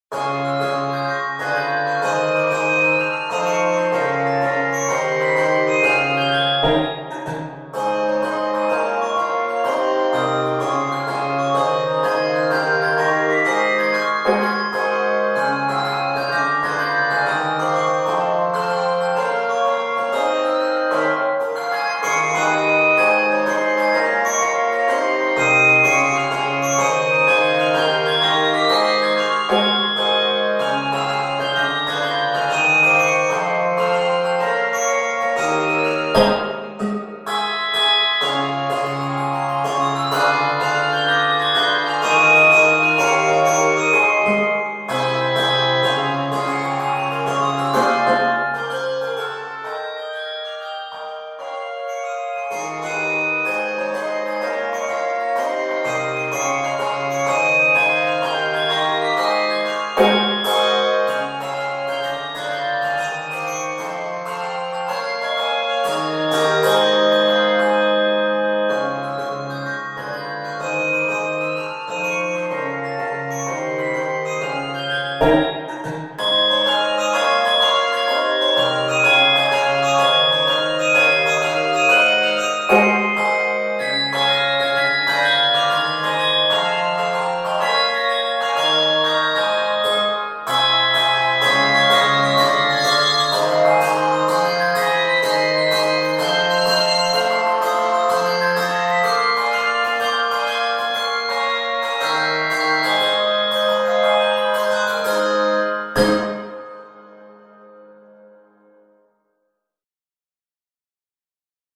handbells
Key of Ab Major.
Octaves: 3-5